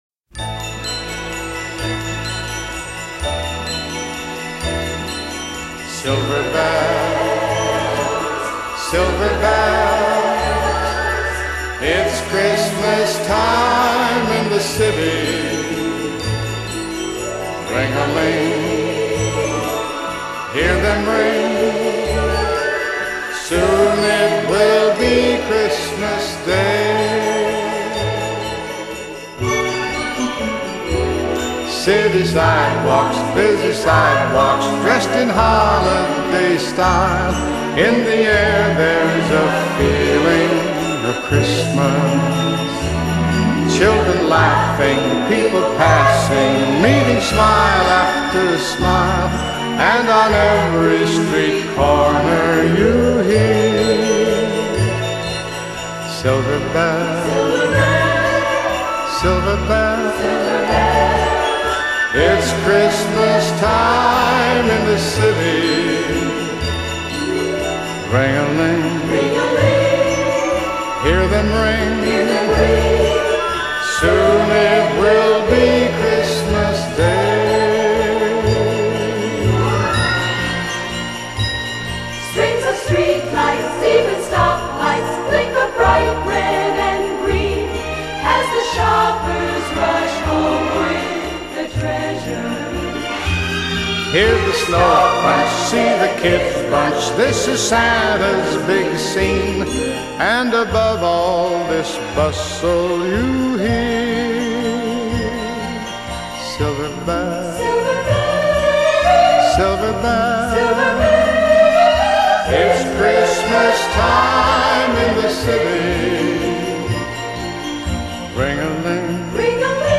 类　　别: Christmas, Pop　　　　　　　　　　  　　.